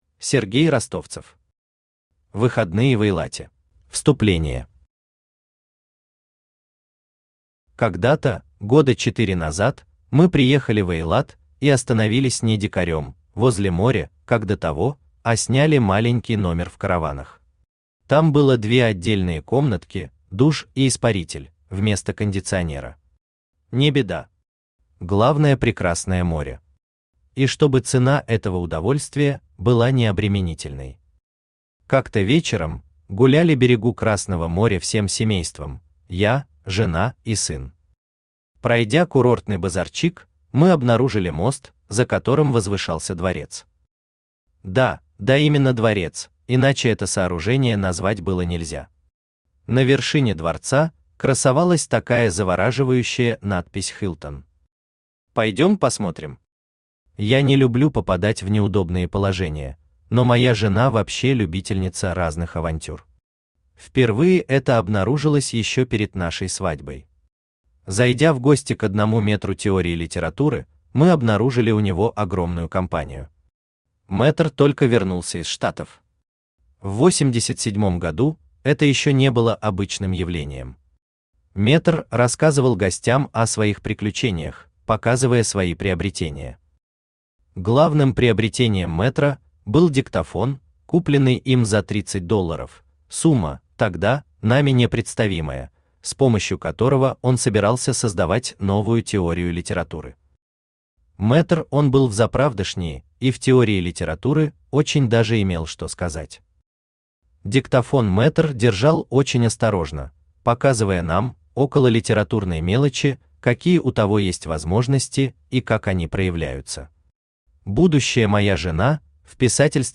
Аудиокнига Выходные в Эйлате | Библиотека аудиокниг